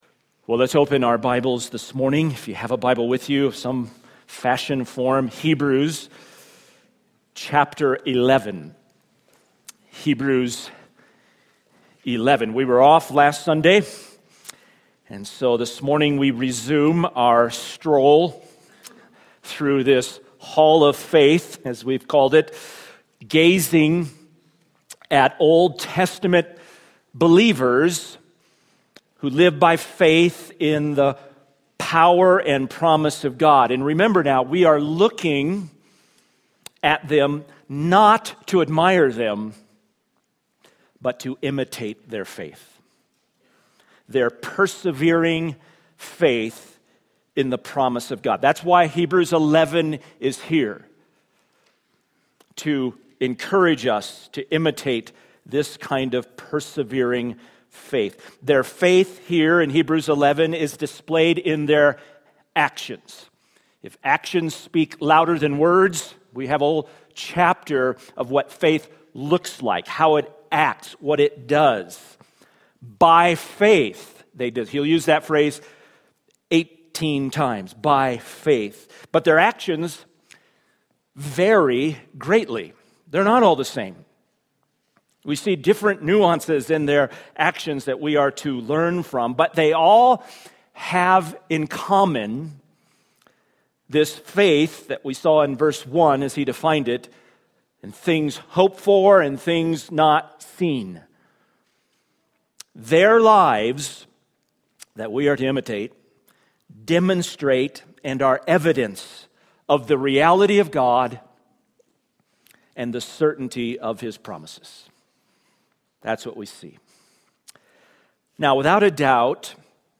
SERMON – Crossroads Bible Church